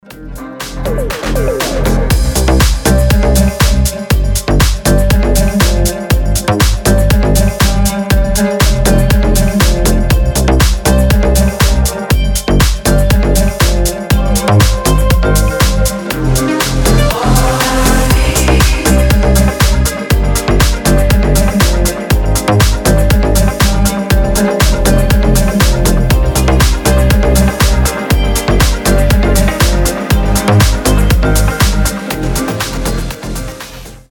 • Качество: 320, Stereo
deep house
восточные
Красивая восточная мелодия